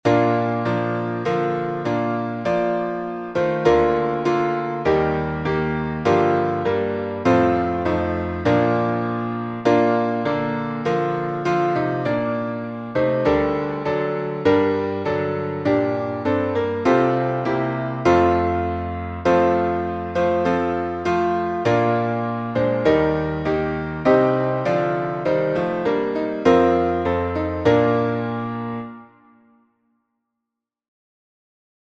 Key signature: B flat major (2 flats) Time signature: 4/4